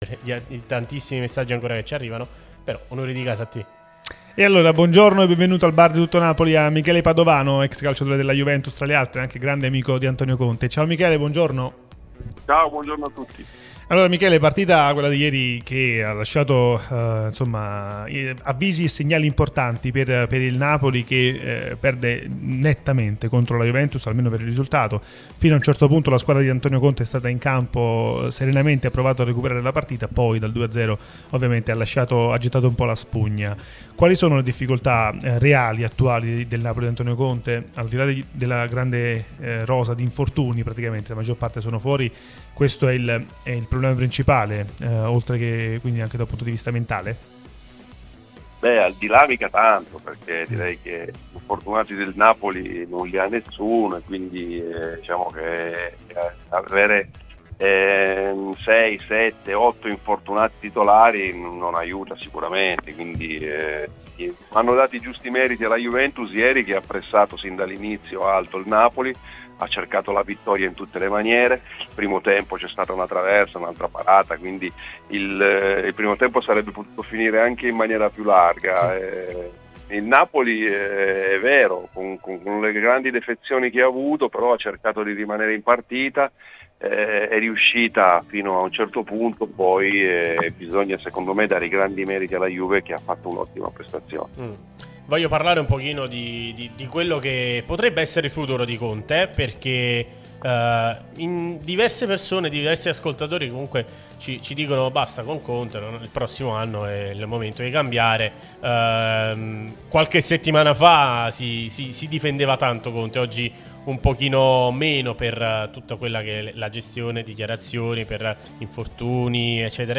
Michele Padovano, ex calciatore della Juve ed amico di Antonio Conte, è intervenuto al Bar di Tuttonapoli su Radio Tutto Napoli, prima radio tematica sul Napoli, che puoi ascoltare/vedere qui sul sito, in auto col DAB Campania o sulle app gratuite (scarica qui per Iphone o qui per Android): "Il risultato è netto, ma fino a un certo punto il Napoli è rimasto in partita.